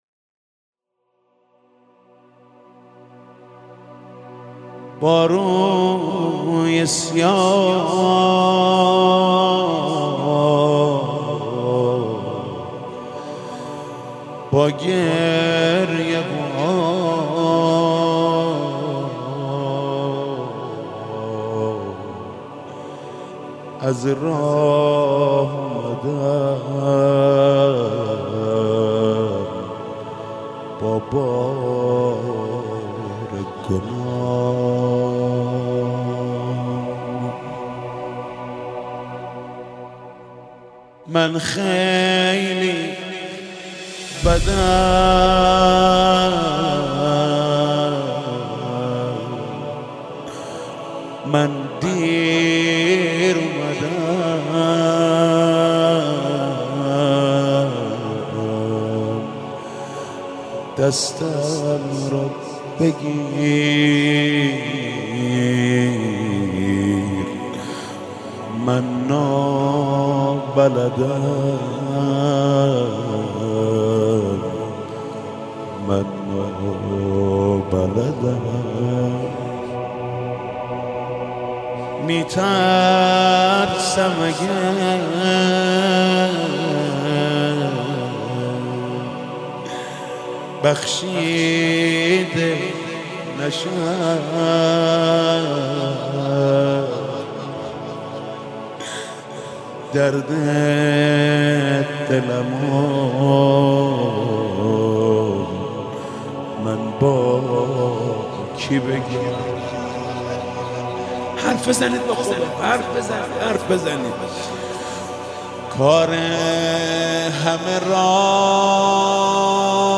استودیویی "با روی سیاه "